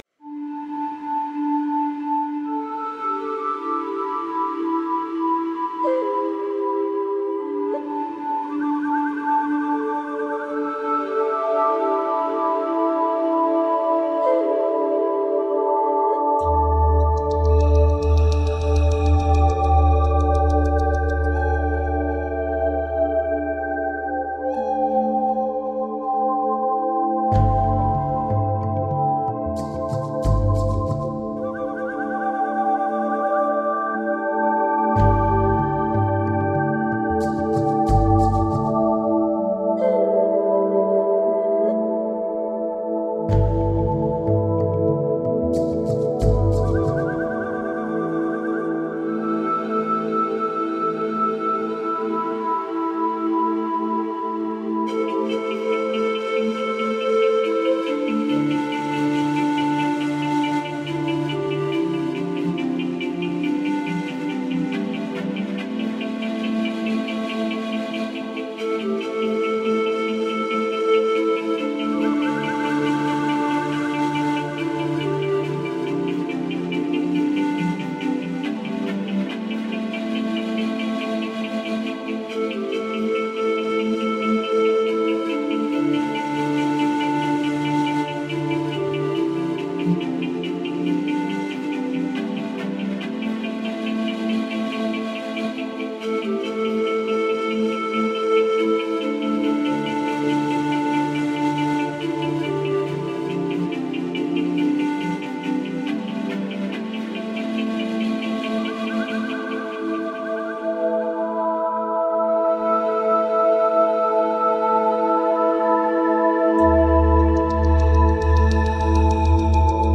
New age/world music for mankind from russia.
Tagged as: New Age, Inspirational, Chillout, Massage